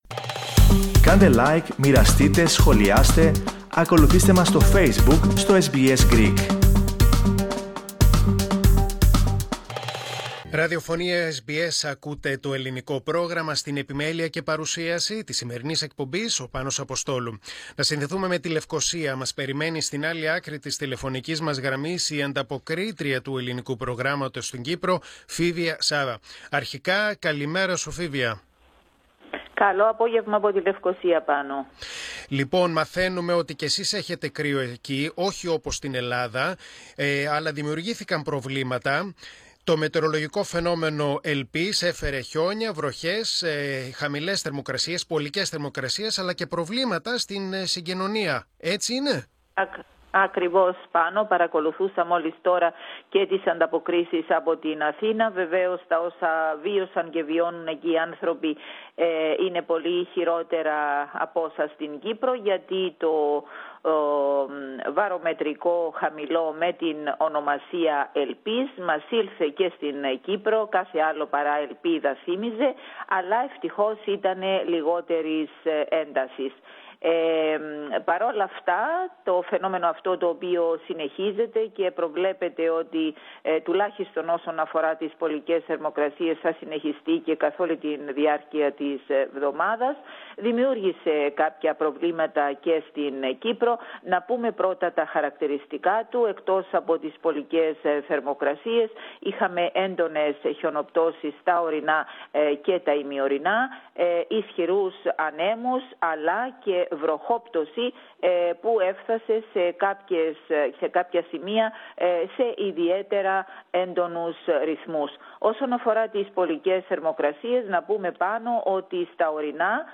Ακούστε ολόκληρη την ανταπόκριση από την Κύπρο, πατώντας το σύμβολο στο μέσο της κεντρικής φωτογραφίας.